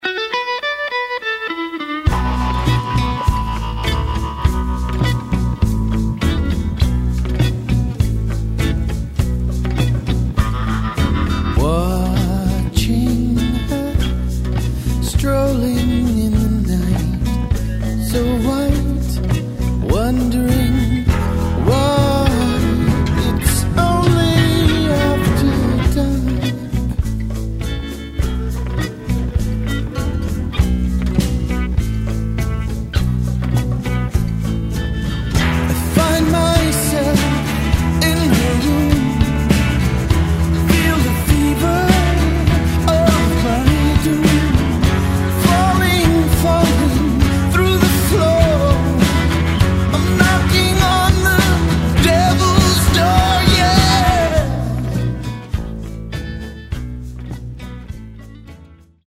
Рок рингтоны
Красивый мужской голос , Латиноамериканские , Блюз-рок